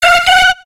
Cri de Natu dans Pokémon X et Y.